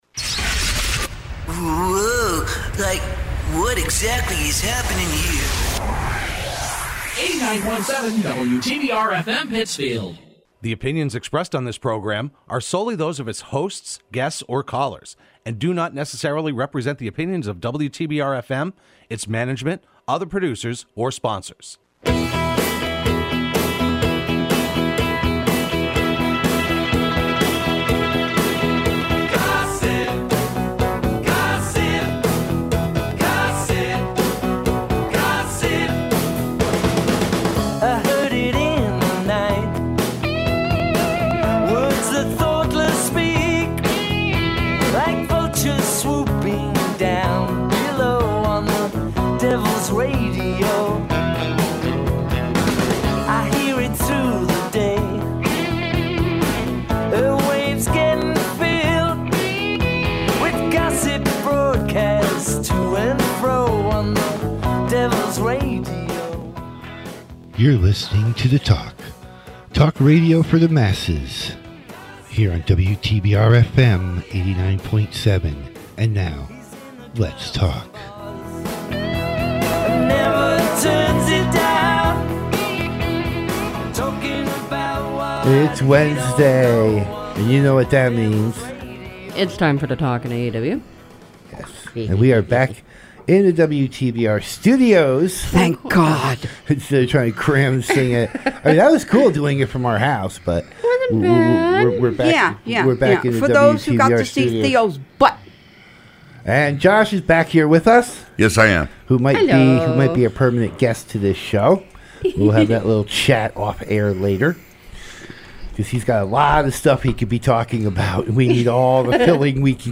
Broadcast every Wednesday morning at 9:00am on WTBR.